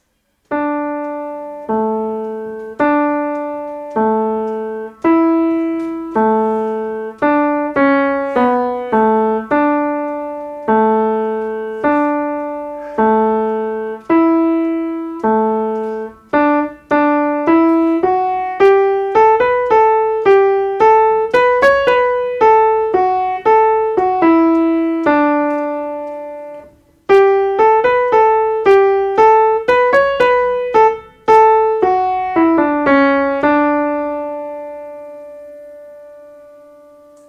2ème voix
La_colline_aux_corallines_2eme_voix.mp3